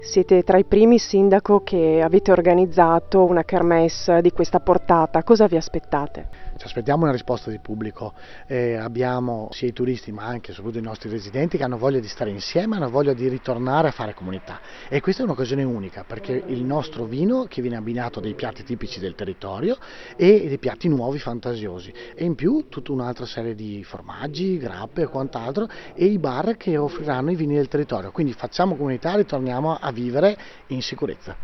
Giovanni-Dal-Cero-sindaco-Castelnuovo.mp3